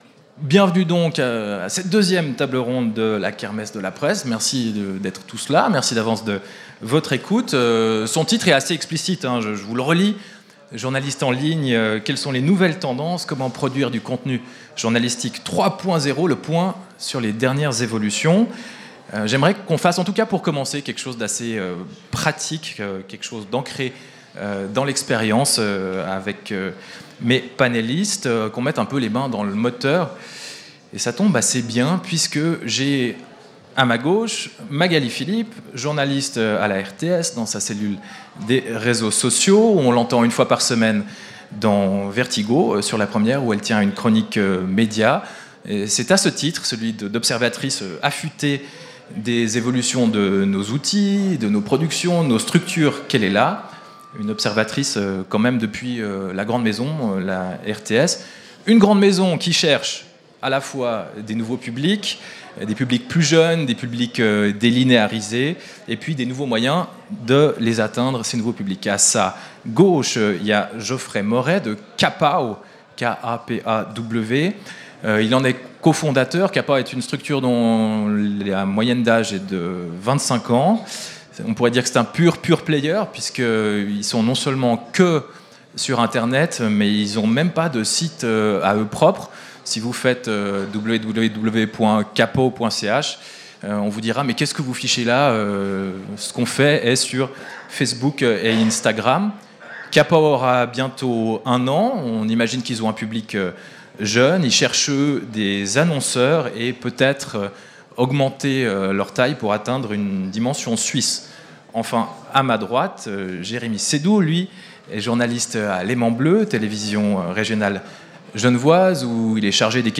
Radio Django retransmet et vous propose de découvrir les conférences de la 1ere Kermesse de la presse :
TABLE-RONDE-2.mp3